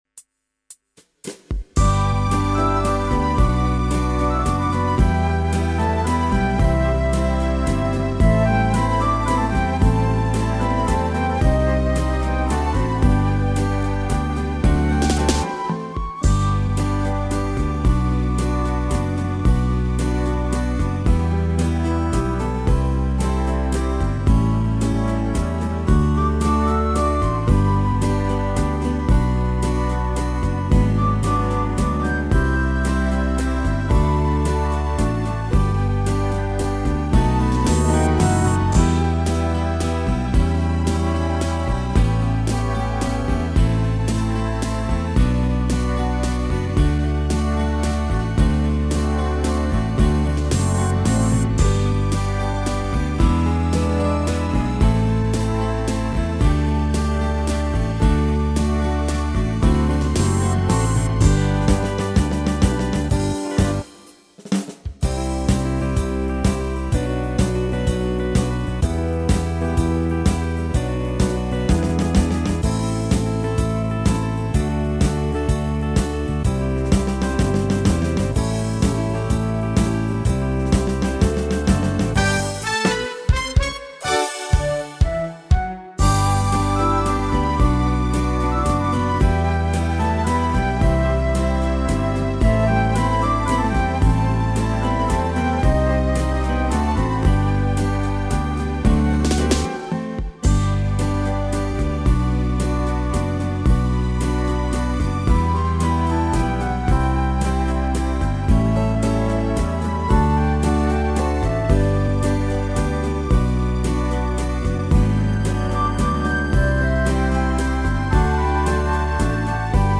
Tone Nam (F# hoặc G)